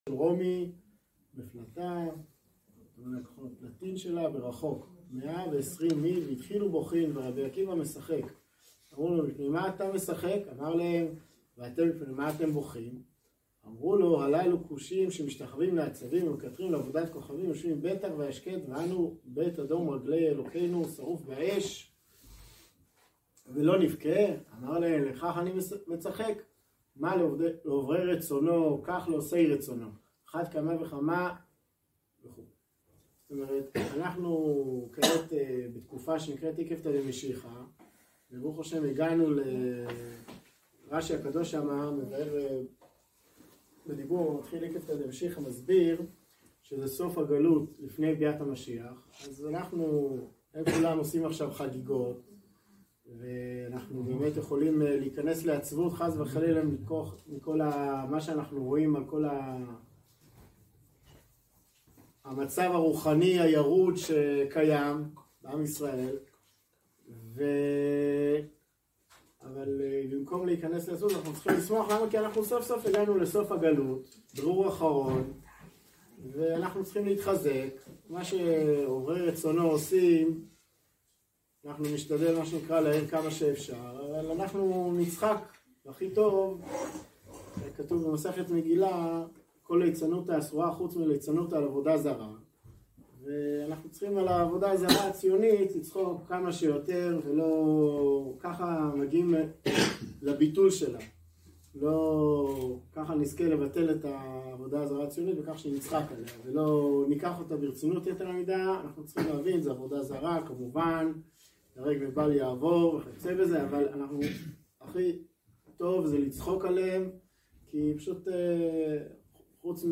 שיעור ברכסים על יום אידם שבדו מלבם המכונה ׳יום העצמאות׳, אור ליום ג׳ בניסן ה׳תשפ״א, רכסים